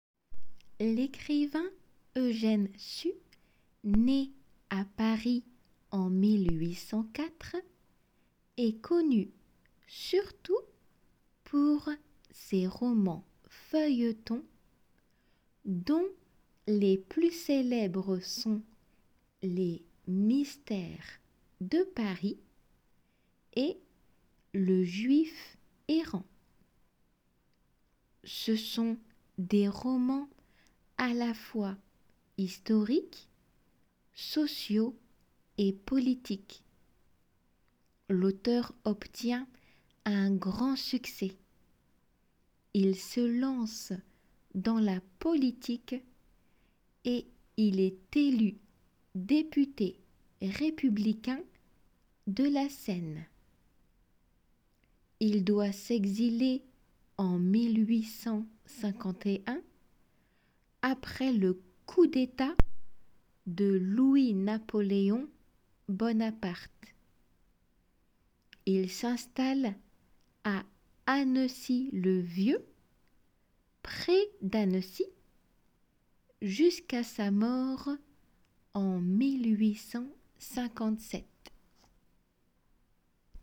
読解　聞き取り練習用。
il est élu ーリエゾン イレテル。